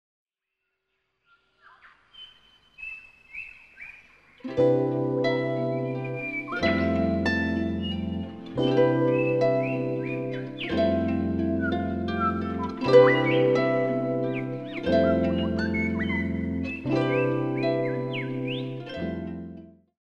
Featuring the harp, piano & guitar
Recorded at Healesville Sanctuary